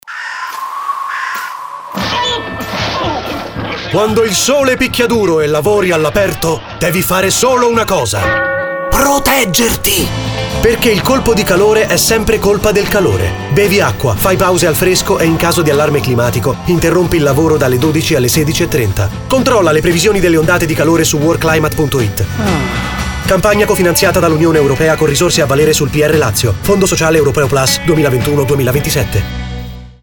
Il tono della campagna è leggero e paradossale: mostra come il caldo eccessivo possa portare a comportamenti assurdi e fuori luogo, senza però generare ansia o colpevolizzazione.
04_spot radio_Emergenzacaldo30sec.mp3